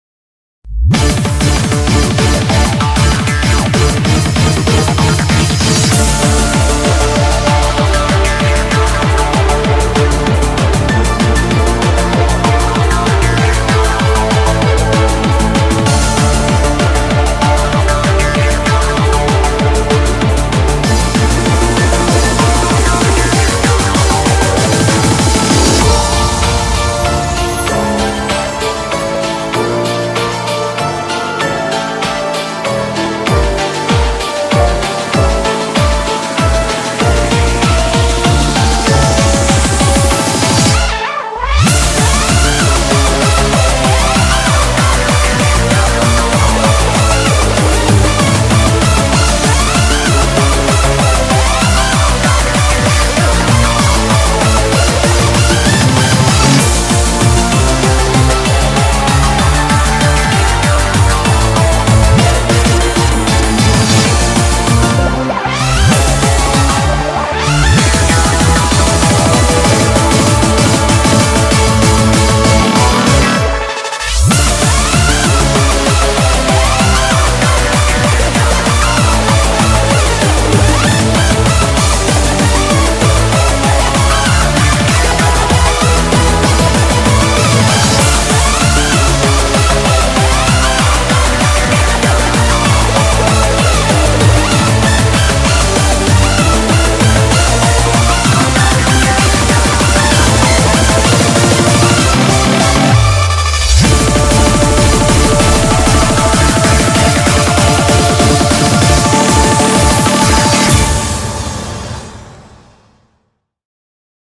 BPM193
Audio QualityLine Out